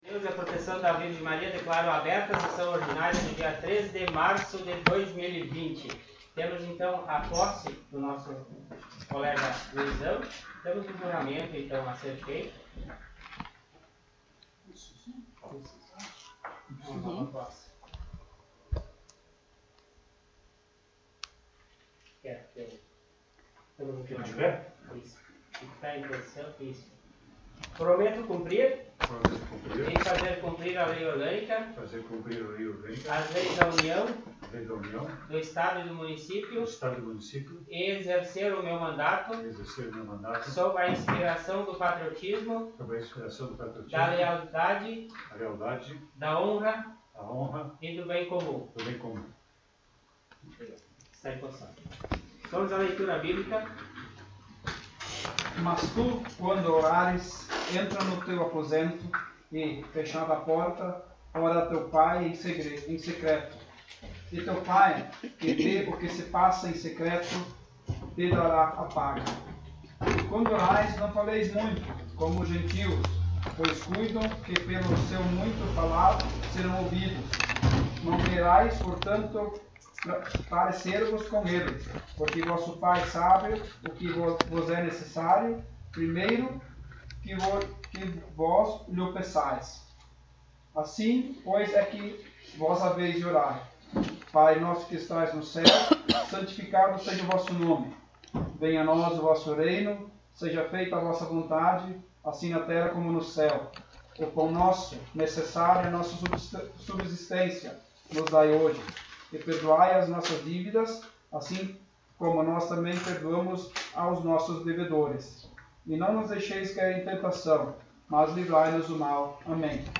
Sessão Ordinária do dia 03 de março de 2020